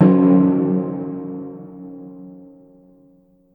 timpani